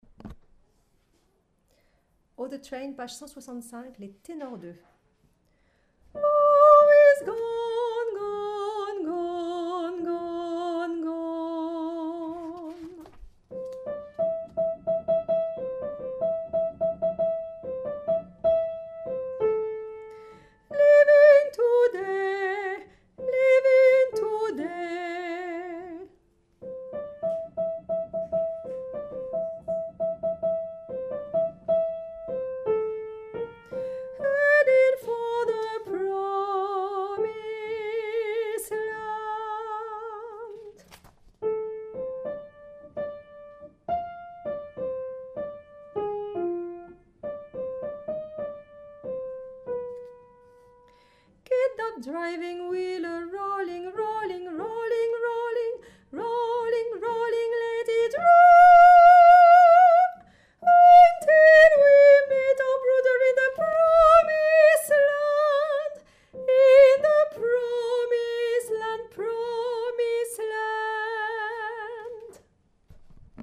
Tenor2
oh-the-train_Tenor2.mp3